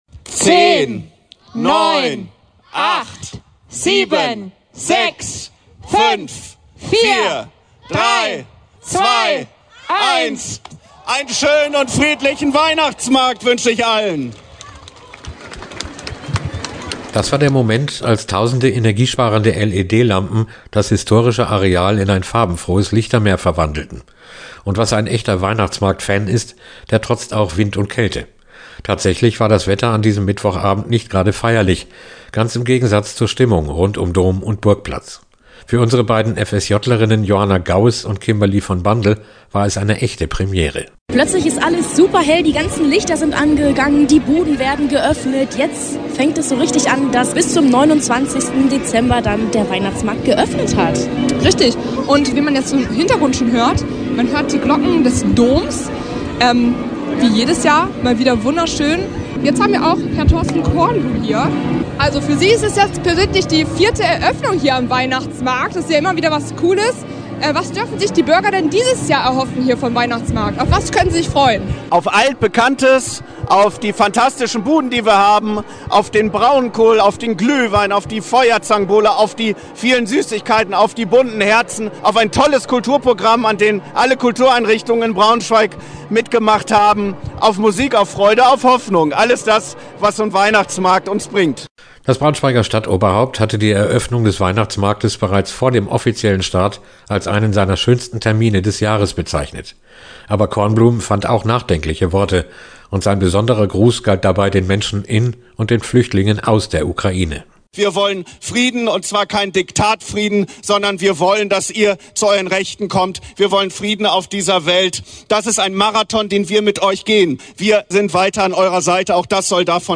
BmE-Start-Weihnachtsmarkt-BS-2024.mp3